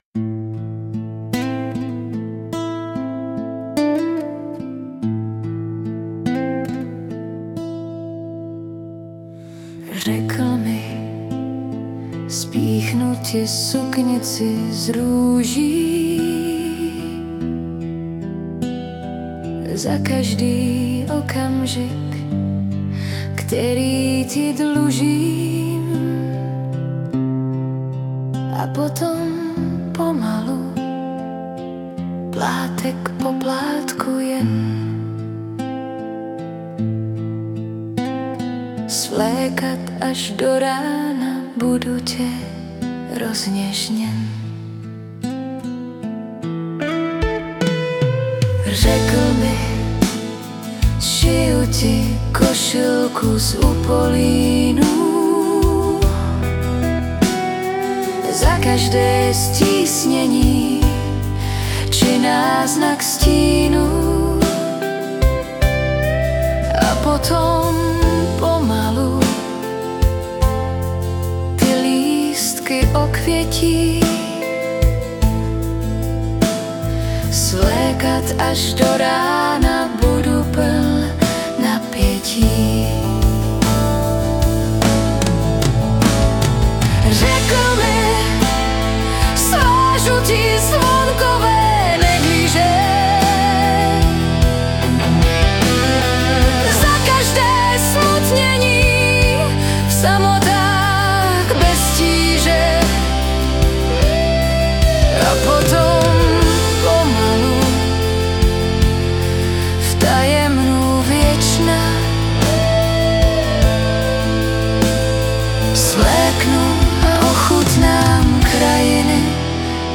Jinak super rockový háv.